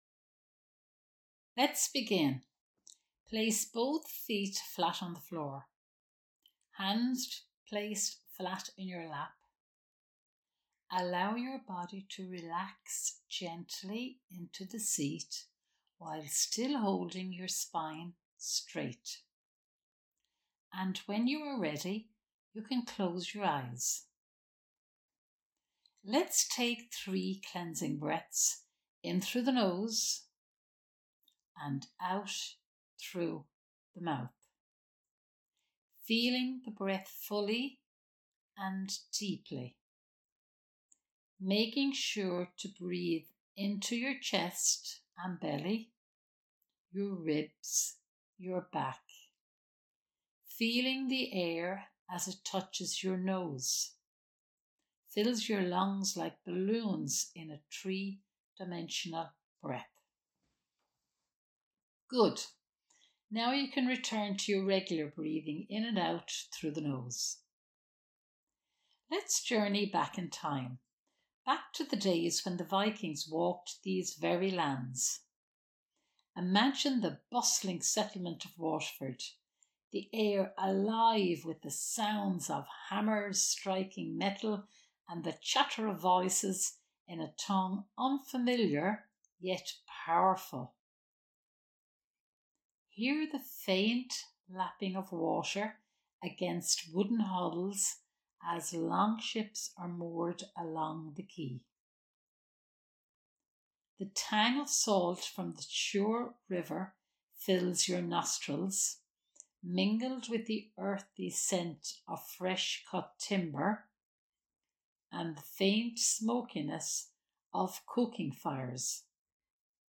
Viking Eco-Warrior Meditation
CC08-meditation.mp3